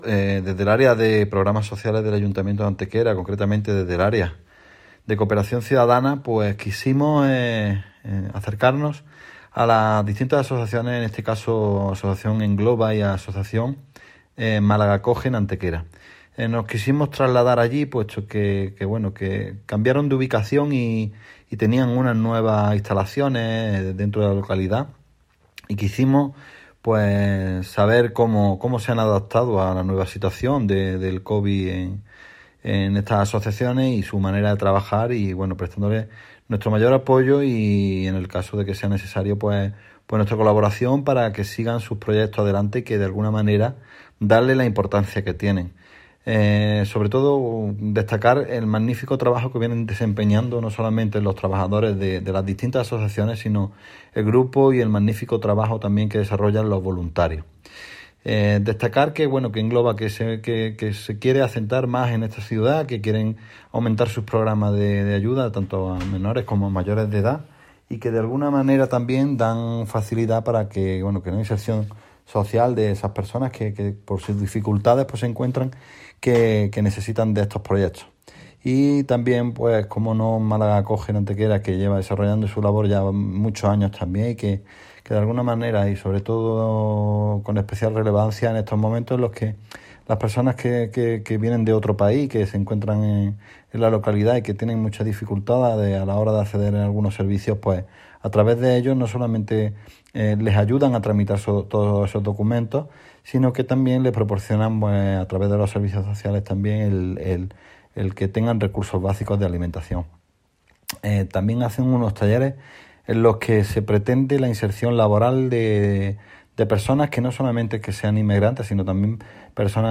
El teniente de alcalde delegado de Programas Sociales, Igualdad y Cooperación Ciudadana del Ayuntamiento de Antequera, Alberto Arana, visitaba recientemente las sedes en nuestra ciudad de los colectivos sociales "Asociación Engloba" y "Málaga Acoge" una vez que ya se han terminado de establecer en sus respectivas nuevas ubicaciones tanto de calle Obispo como de calle San José respectivamente.
Cortes de voz